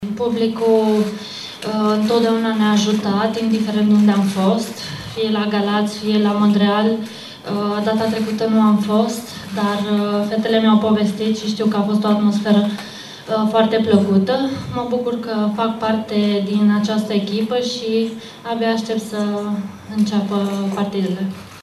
Irina Begu, care a fost accidentată la jocul precedent, aşteaptă un public la fel de activ: